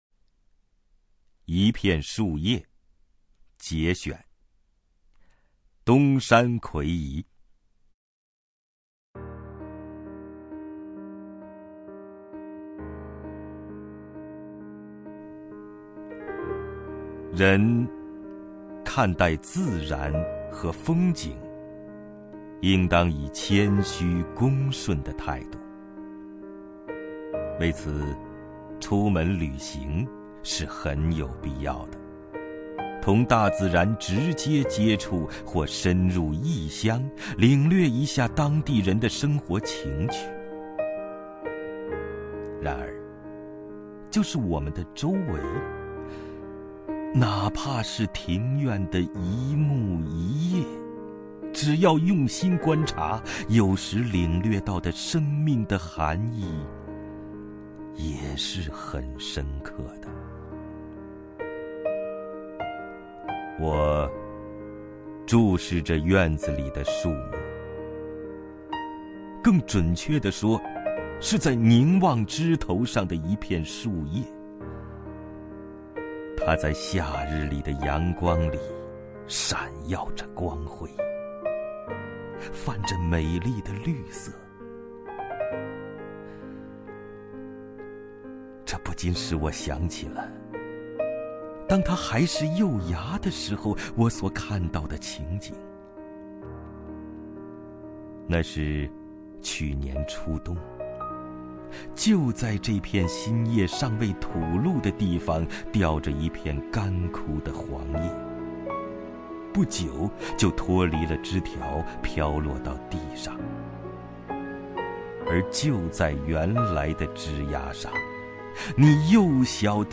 首页 视听 名家朗诵欣赏 王凯
王凯朗诵：《一片树叶（节选）》(（日）东山魁夷)　/ （日）东山魁夷